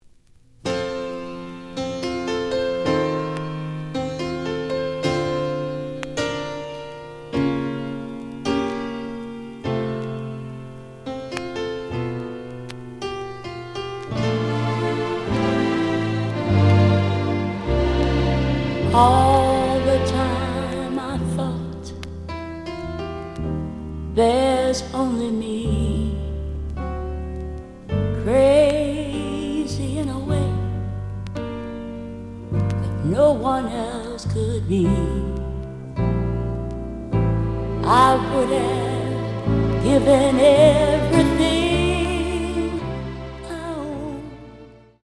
試聴は実際のレコードから録音しています。
●Genre: Soul, 70's Soul
●Record Grading: VG+~EX- (B面の序盤若干ノイジーだが、全体的にはおおむね良好。)